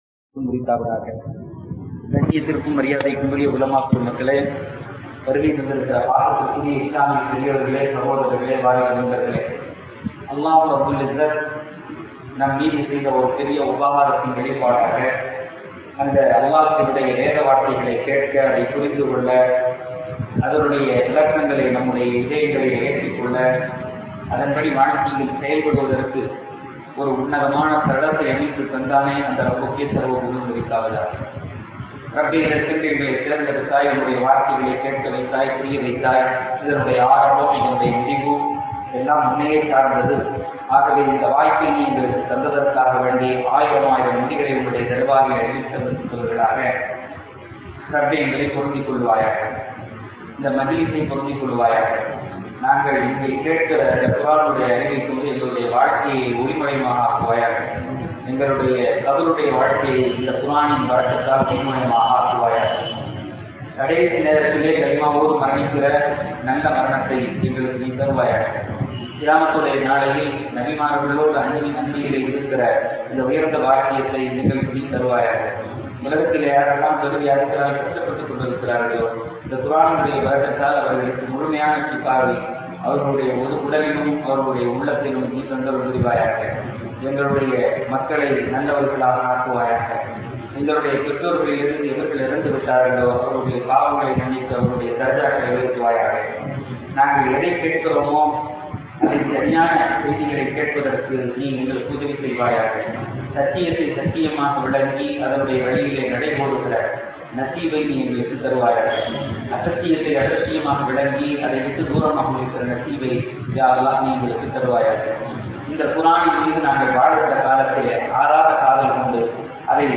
யாஸீன் விளக்கவுரை: ஆயத்: 11-12